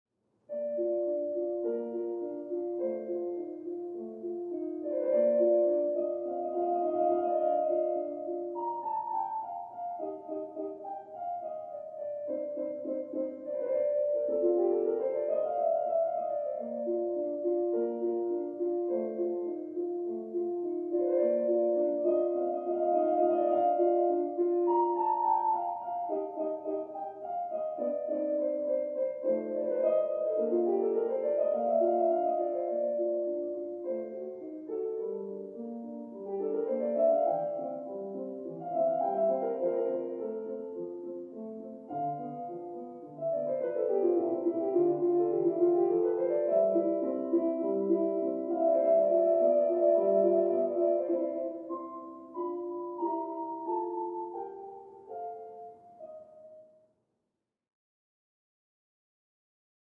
Music; Classical Piano, From Next Room.